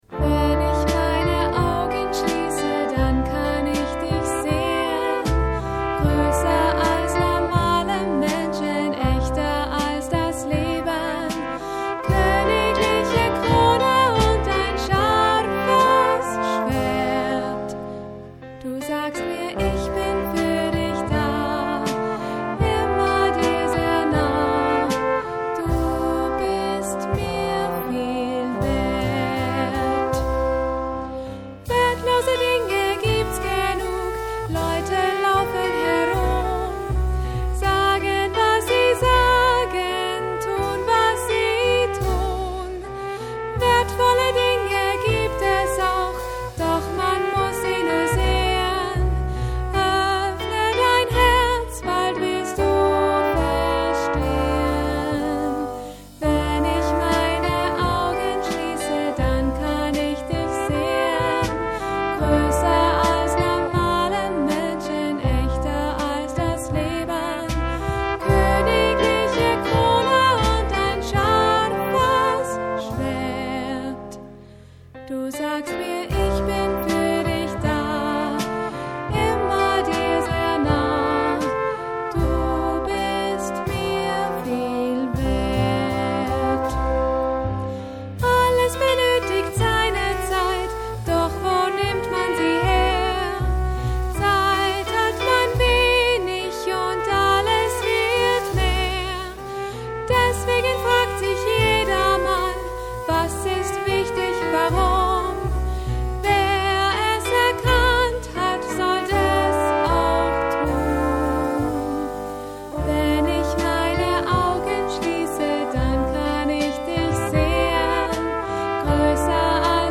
Lieder und Instrumentalstücke aus dem Musical.
Tenor- und Bassposaune
Querflöte, Alt- und Tenorsaxophon, Klavier
Kontrabass, E-Bass
Schlagzeug